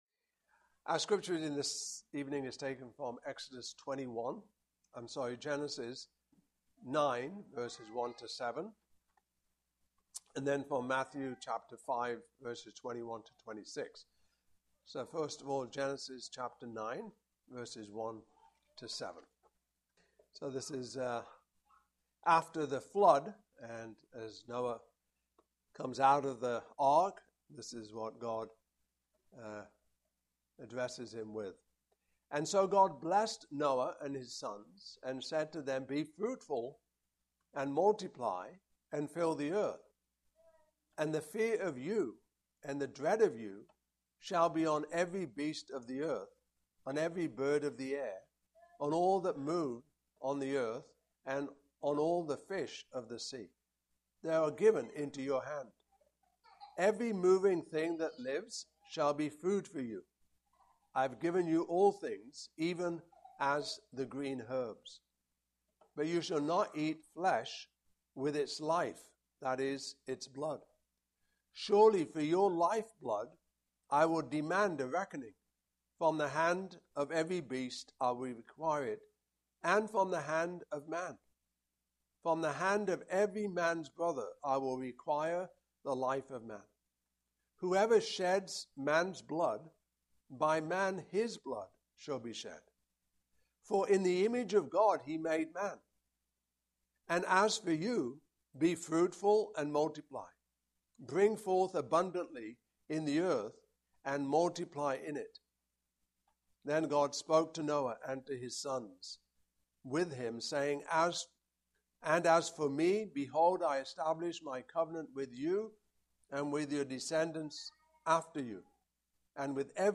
Passage: Genesis 9:1-7; Matthew 5:21-26 Service Type: Evening Service